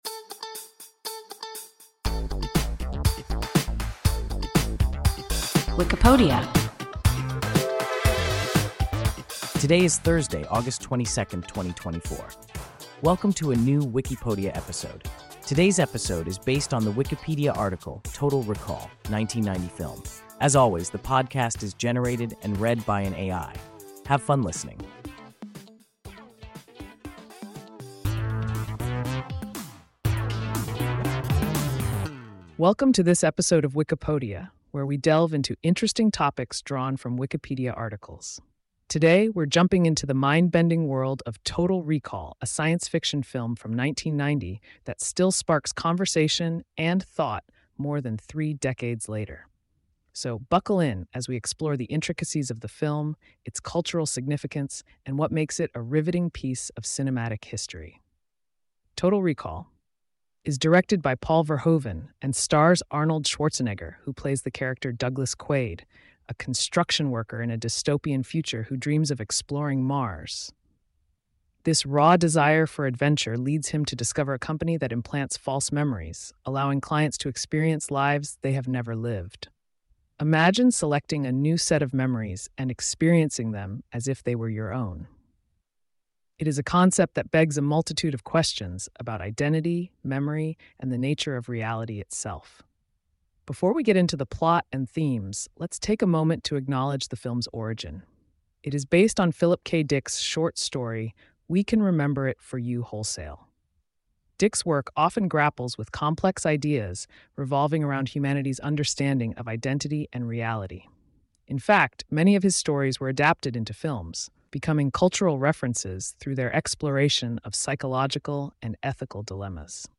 Total Recall (1990 film) – WIKIPODIA – ein KI Podcast